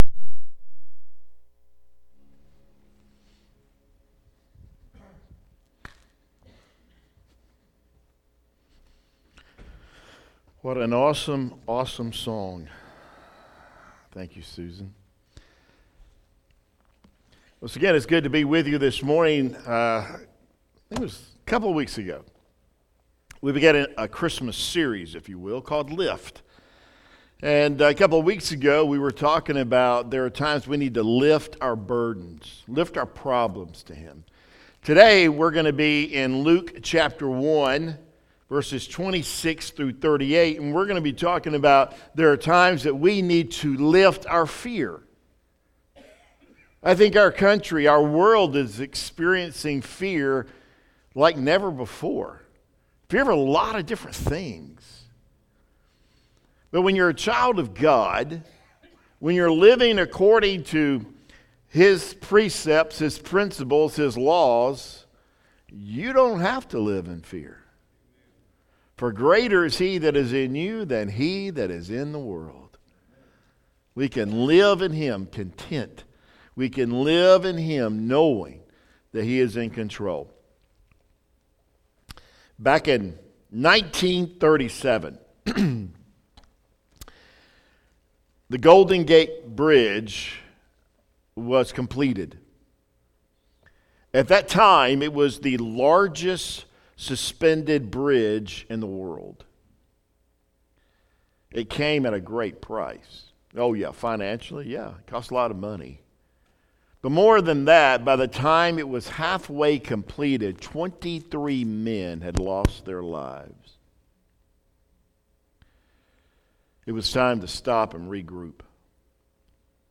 Lake Pointe Baptist Church Weekly Messages